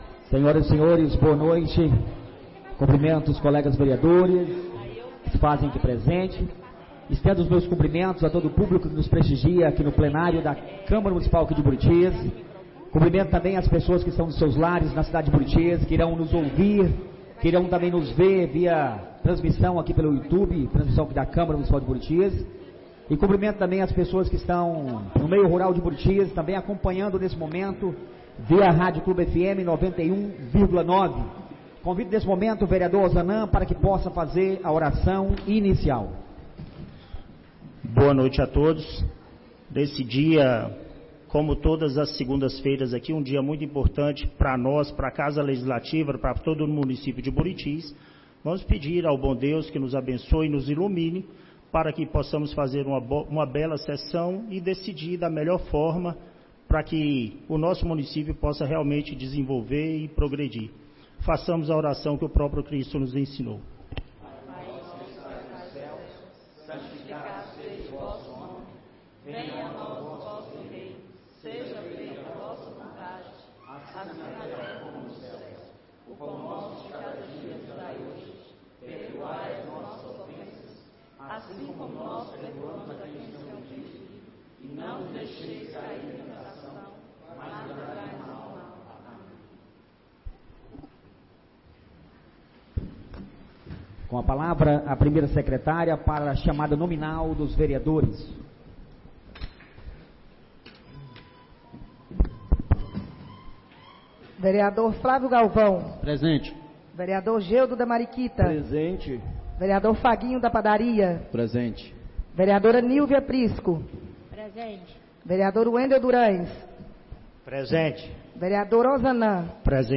39ª Reunião Ordinária da 4ª Sessão Legislativa da 15ª Legislatura - 02-12-24